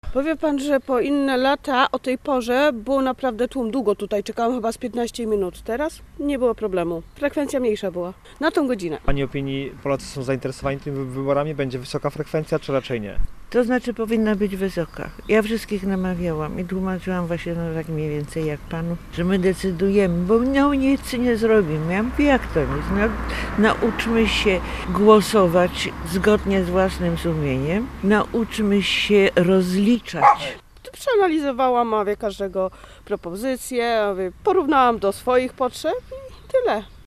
sonda-glosowanie.mp3